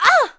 Juno-Vox_Damage_03.wav